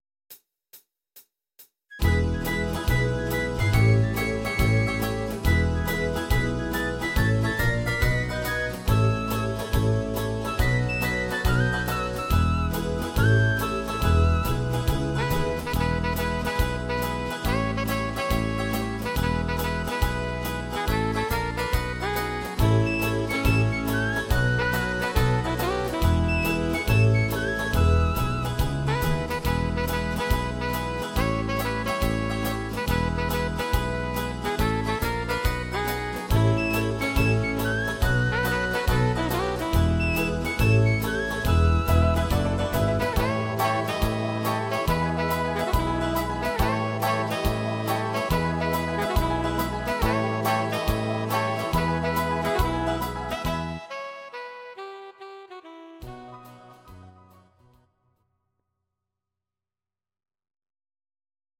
Audio Recordings based on Midi-files
Oldies, Country, 1950s